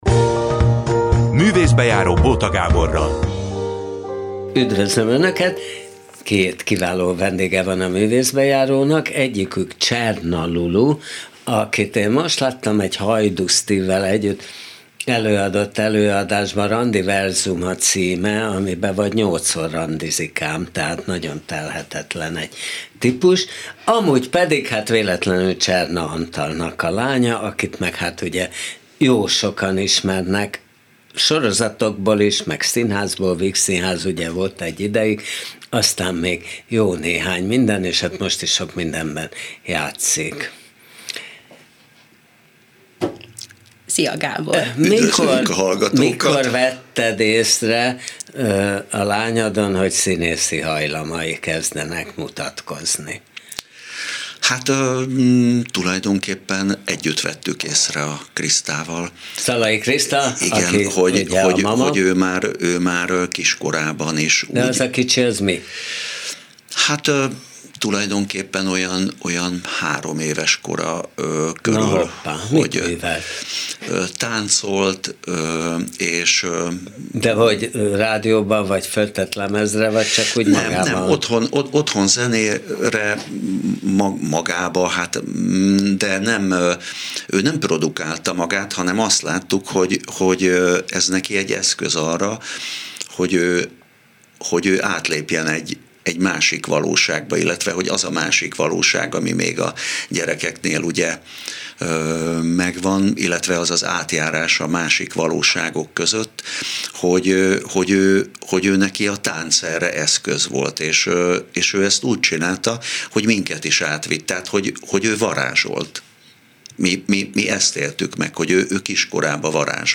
A Művészbejáró elsősorban színházi magazin, amiben minden színpadi műfaj, a próza, az opera, a musical, az operett, a tánc, a báb, a varieté, a cirkusz, képviselteti magát. Neves művészek, feltörekvő ifjak ugyanúgy megszólalnak benne, mint nélkülözhetetlen háttéremberek. Törekszünk az oldott hangulatú, tartalmas beszélgetésekre, fontos teljesítmények, életutak bemutatására, színházi szakmák megismertetésére.